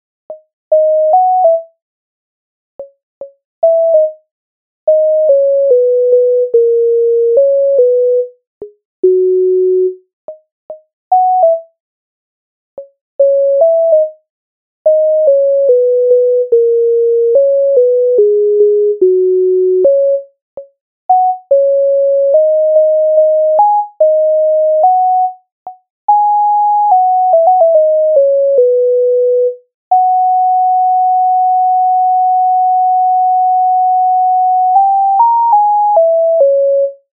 MIDI файл завантажено в тональності H-dur